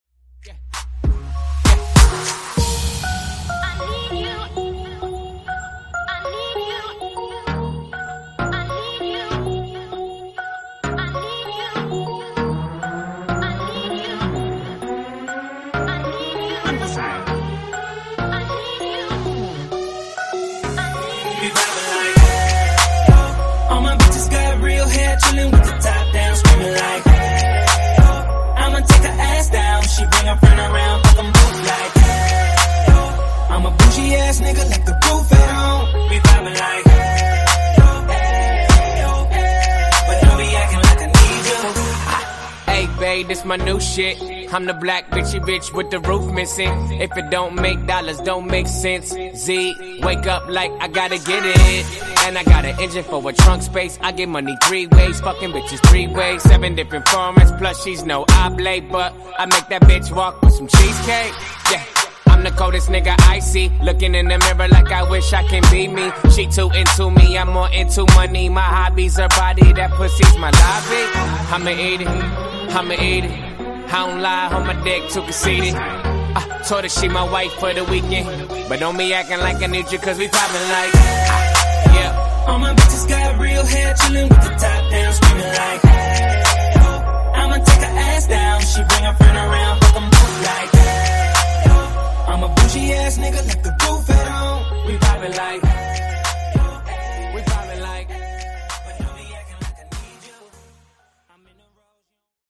Genres: DANCE , EDM , RE-DRUM
Clean BPM: 127 Time